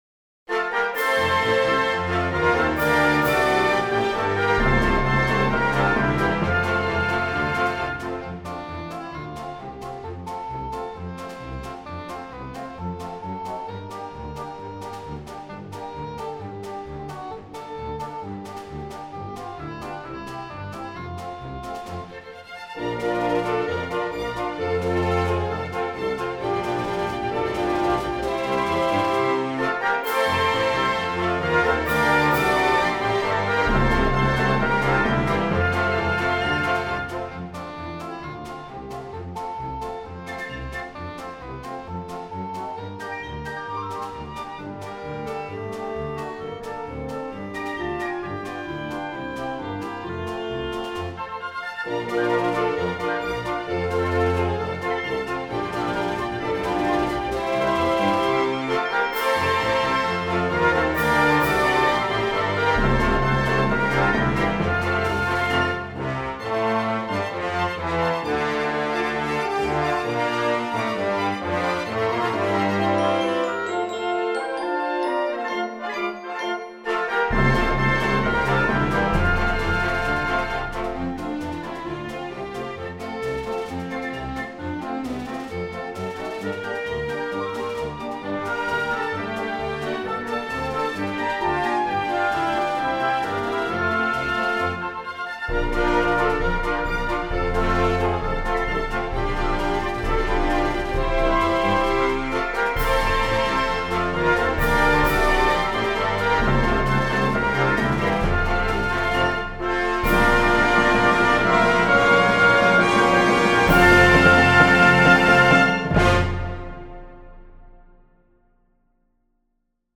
オーケストラ・バージョン
song_orchestra.mp3